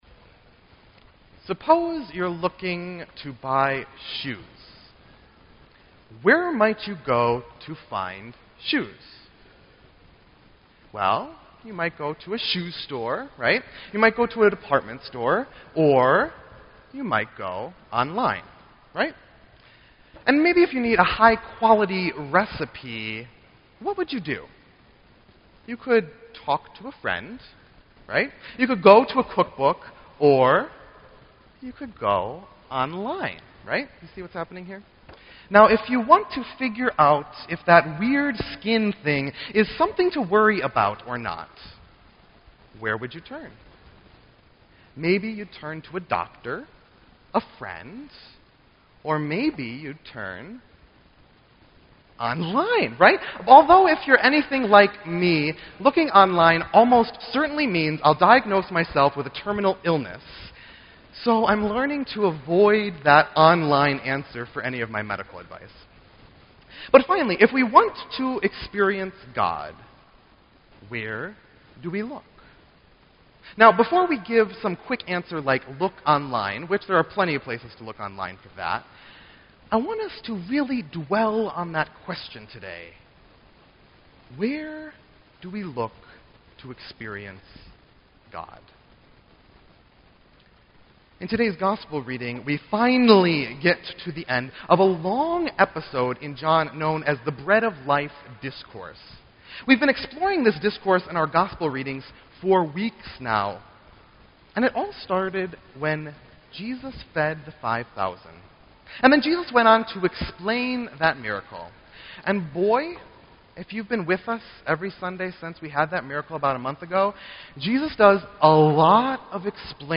Sermon_8_23_15.mp3